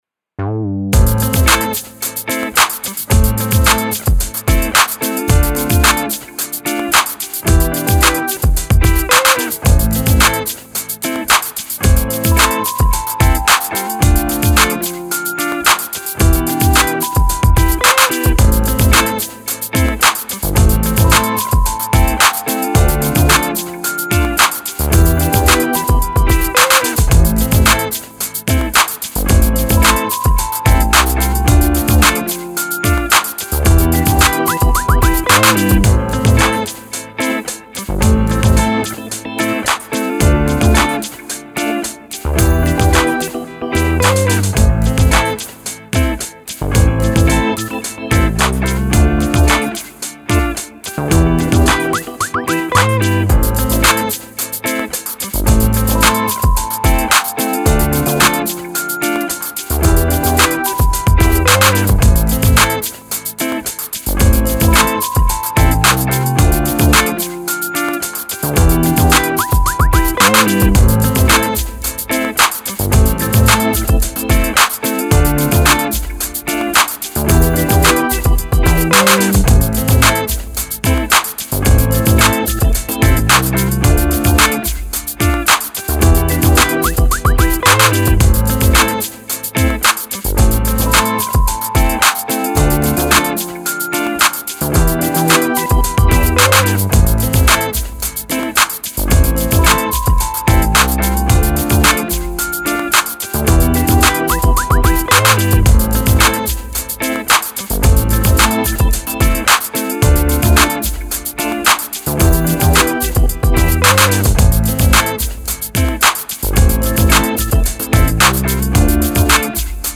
an easygoing RNB track, with some guitar, organ and chimes.
ambient
instrumental
positive
guitar
rnb
blues
lounge
relaxed
dreamy